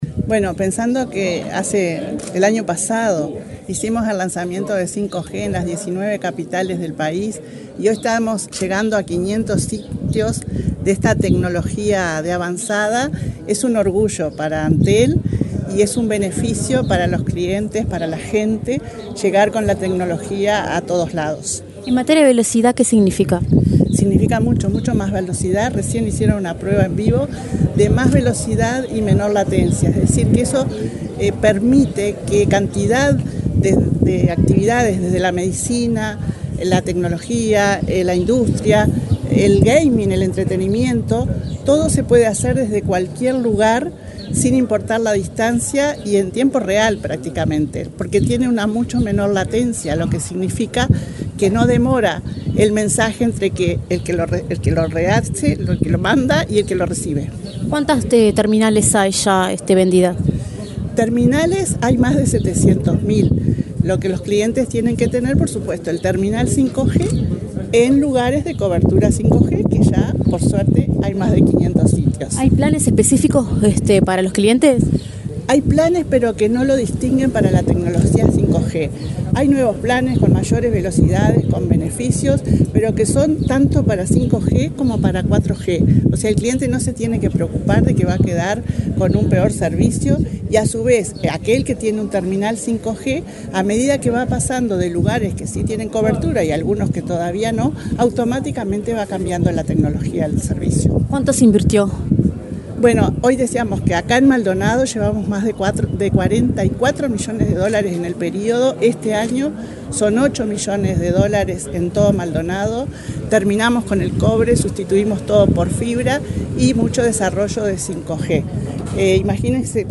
Declaraciones de la presidenta de Antel, Annabela Suburú
Declaraciones de la presidenta de Antel, Annabela Suburú 23/10/2024 Compartir Facebook X Copiar enlace WhatsApp LinkedIn La presidenta de Antel, Annabela Suburú, inauguró un nuevo sitio de tecnología 5G de infraestructura móvil en la ciudad de Aiguá, departamento de Maldonado. Luego, dialogó con la prensa.